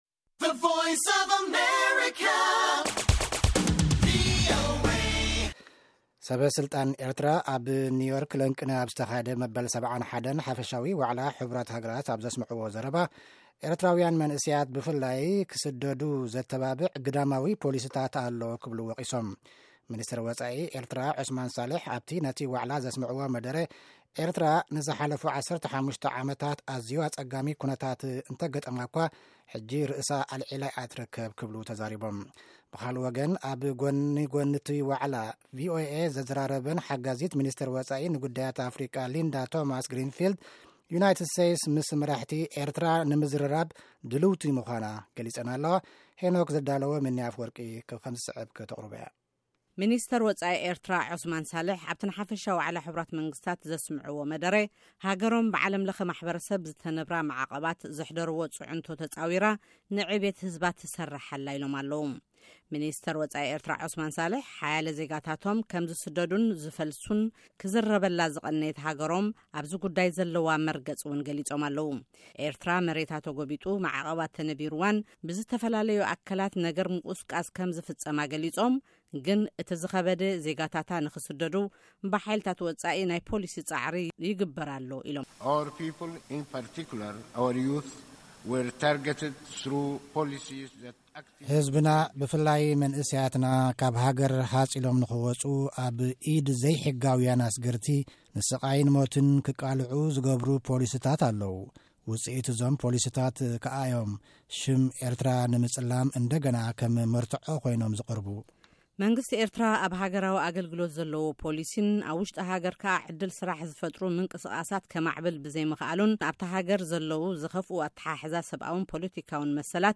ሰበ-ስልጣን ኤርትራ ኣብ ኒው ዮርክ ለንቅነ ኣብ ዝተኻየደ ሓፈሻዊ ጉባኤ ሕቡራት ሃገራት ኣብ ዘስምዕዎ መደረ። ኤርትራውያን መንእሰያት ብፍላይ ንኽስደዱ ዘተባብዕ ግዳማዊ ፖሊሲታት ኣለዉ ክብሉ ወቒሶም።